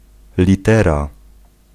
Ääntäminen
Synonyymit courrier texte Ääntäminen France: IPA: [lɛtʁ] Tuntematon aksentti: IPA: /lɛtʁ(ə)/ Haettu sana löytyi näillä lähdekielillä: ranska Käännös Ääninäyte Substantiivit 1. litera {f} 2. list {m} Suku: f .